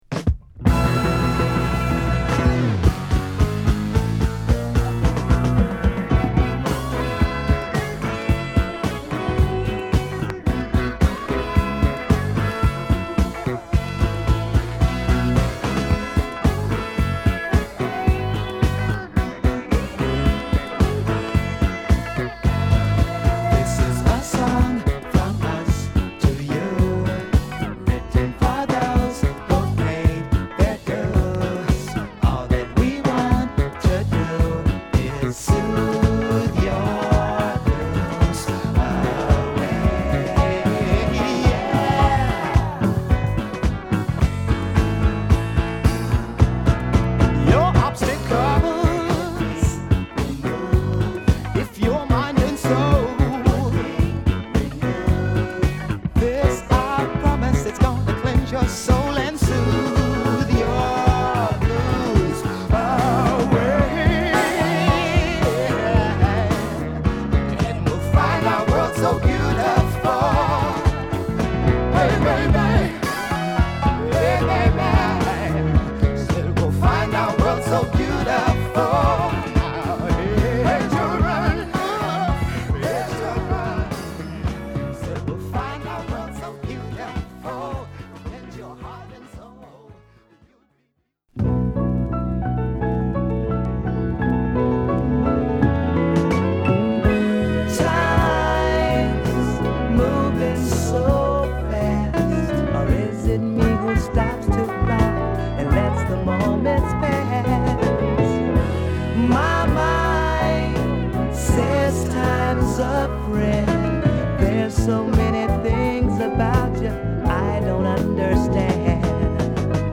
ポジティブな雰囲気を持ったメロのブギー・ファンクチューン！